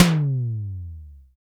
BENDYTOM LO.wav